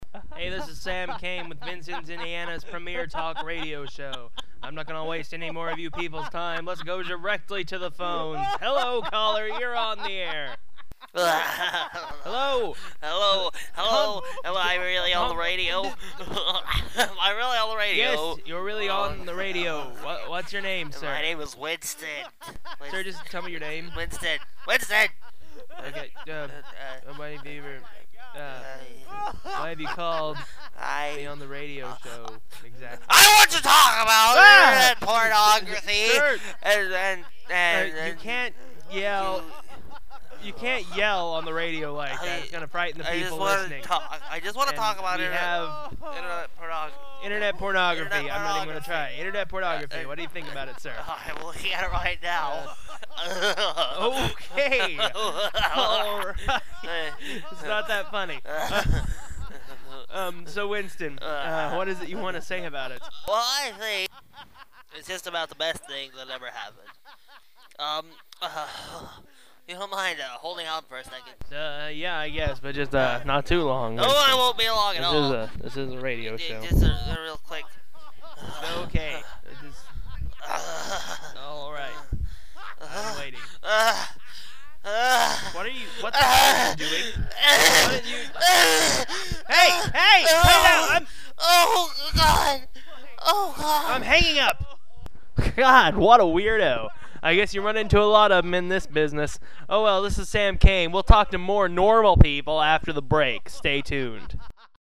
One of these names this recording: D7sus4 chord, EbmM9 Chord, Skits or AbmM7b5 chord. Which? Skits